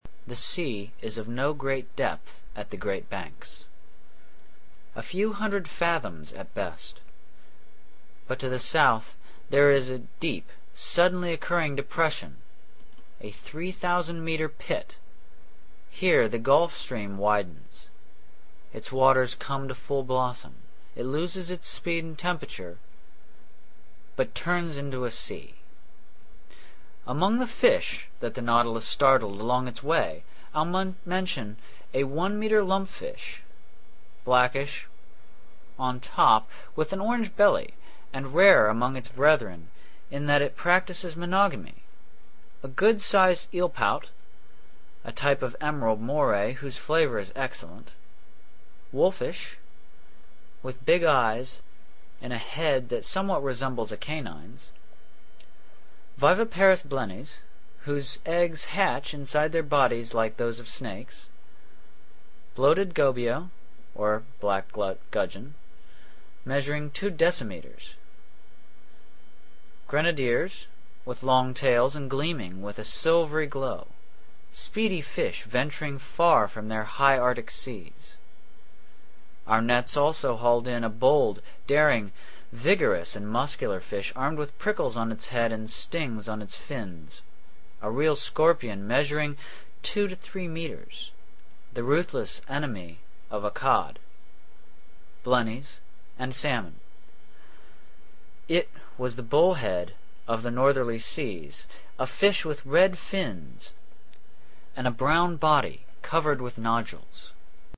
在线英语听力室英语听书《海底两万里》第528期 第33章 北纬47.24度, 西经17.28度(3)的听力文件下载,《海底两万里》中英双语有声读物附MP3下载